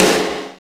Index of /90_sSampleCDs/Roland L-CD701/SNR_Snares 2/SNR_Sn Modules 2
SNR CRUDDY0P.wav